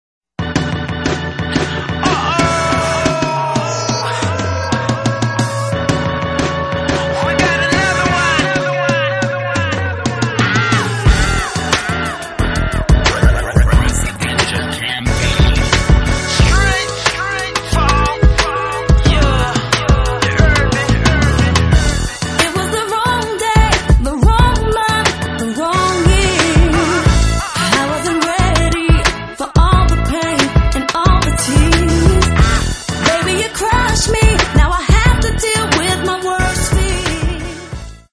Sorties R'nB
plusieurs morceaux up-tempo  efficace
dynamique, joyeux et dansant